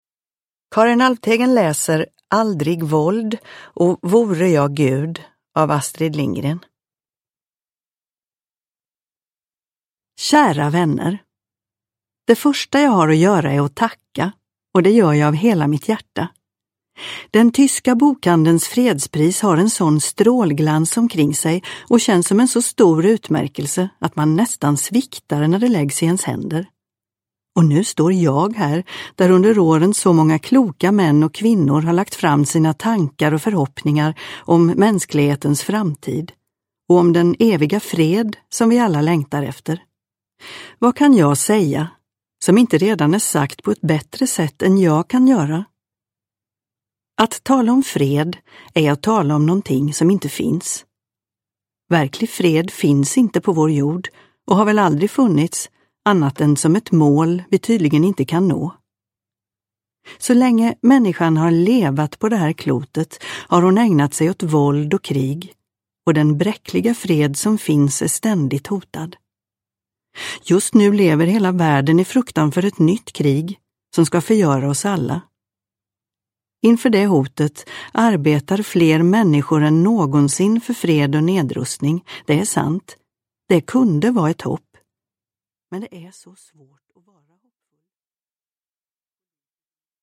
Aldrig våld! (ljudbok) av Astrid Lindgren